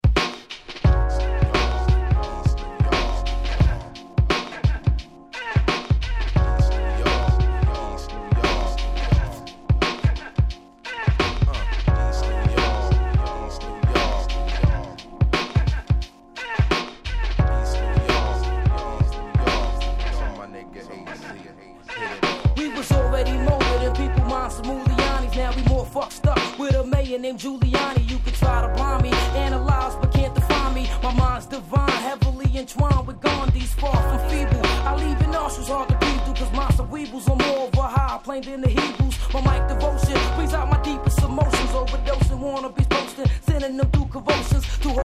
95' Hip Hop Classic !!